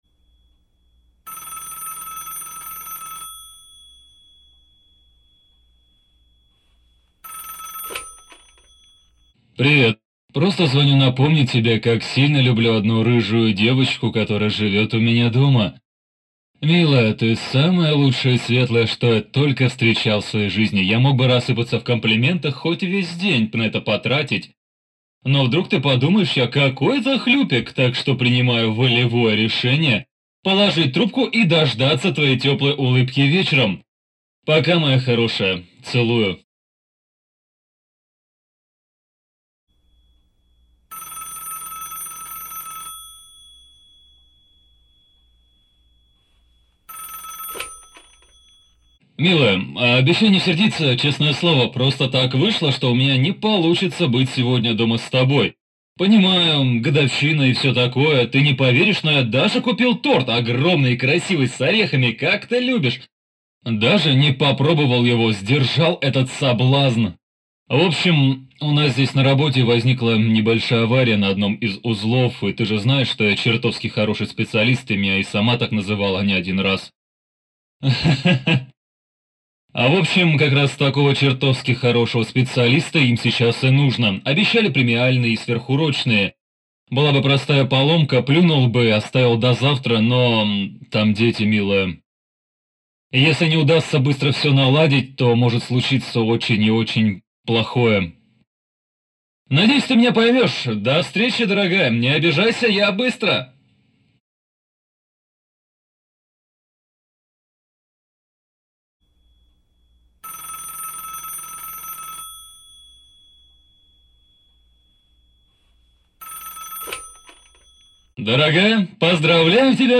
Дикторы мужчины. Озвучка мужским голосом онлайн любых проектов!
Скачать демо диктора